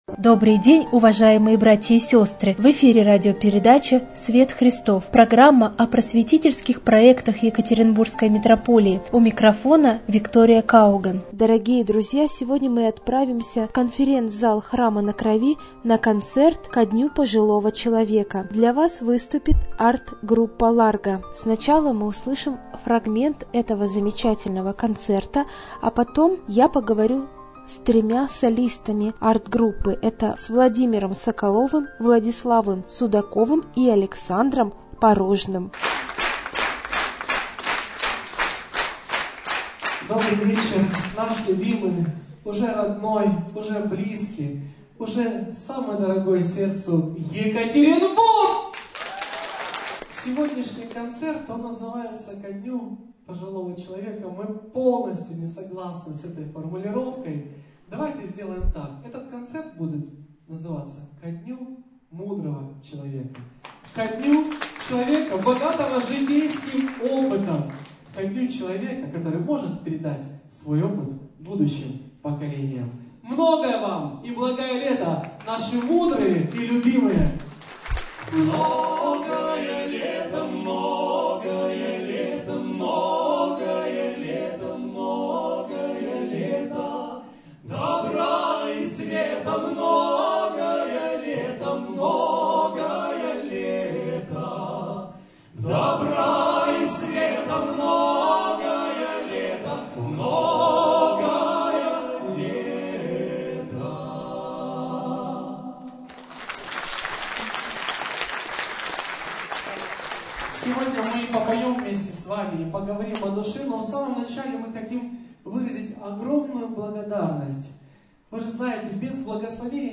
Концерт ко дню пожилого человека от арт-группы "Ларго"